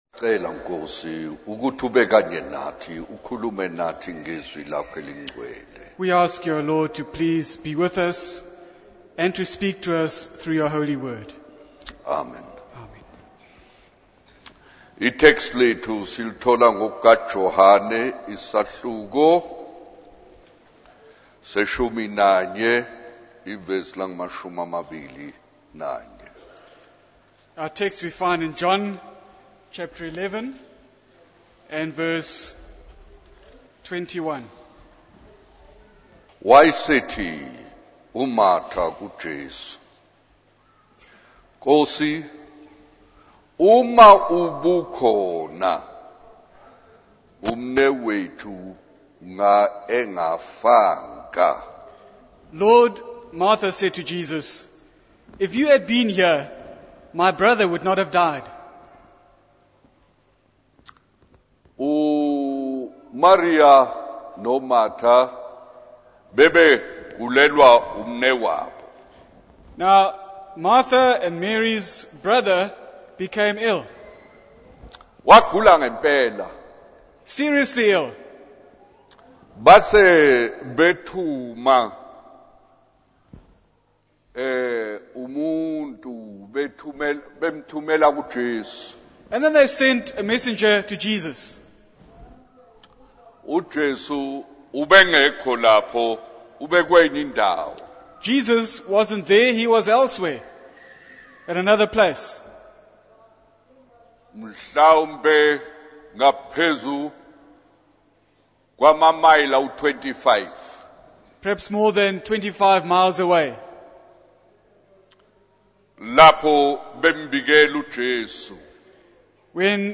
In this sermon, the preacher discusses the negative impact of jealousy and communism on individuals and communities. He shares a story about a gifted and intelligent person who refuses a promotion out of fear of jealousy and harm from others.